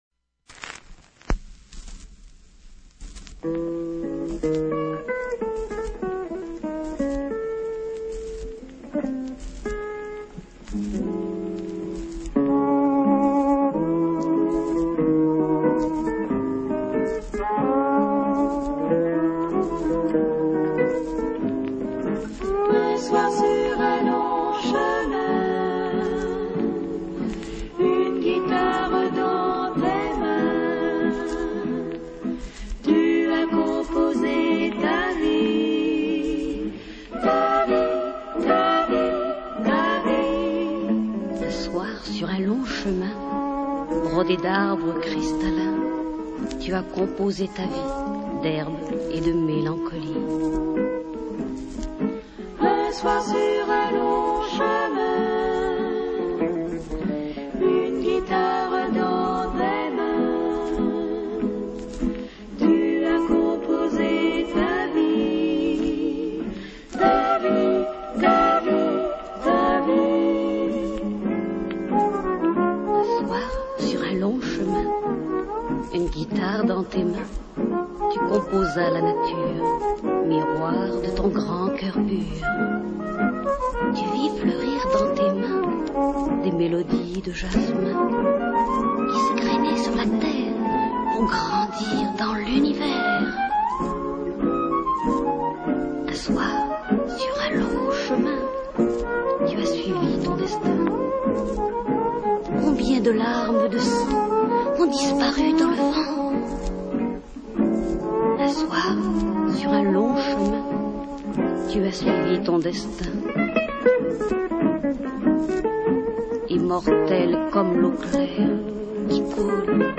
both versions sped up a half step.